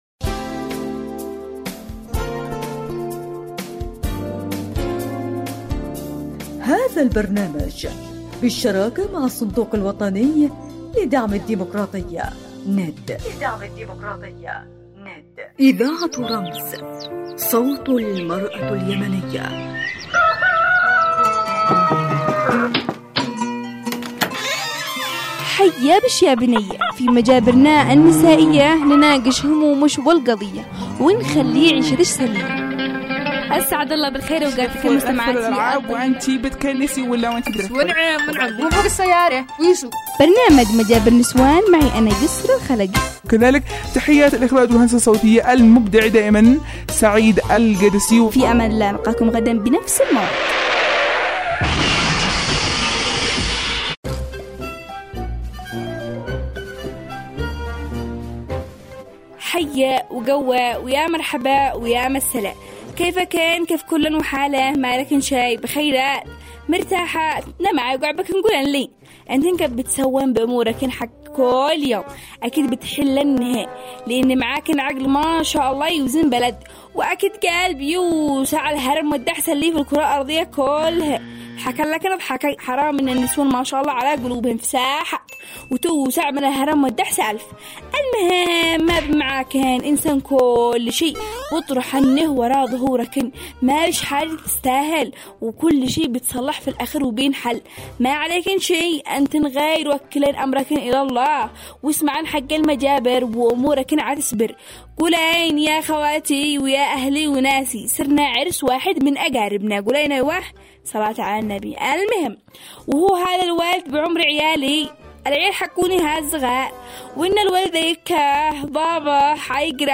موضوع الحلقة / اختبار شريك الحياة و أثره في بناء الأسرة -------------------- دردشات و تفرطه نسائية تُروى باللهجة العامية حول ما يخص المرأة من قضايا مجتمعيه واسريه بالإضافة الى طبخات شعبيه .. -------------------- برنامج مجابرة نسوان برنامج اجتماعي ينافش القضايا الخاصة بالنساء باسلوب كوميدي وباللهجه العامية الصنعانية